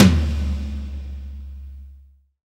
TOM XTOML0GR.wav